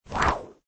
Hiệu ứng âm thanh Tiếng Vung dao, Quơ gậy vụt - Tải Mp3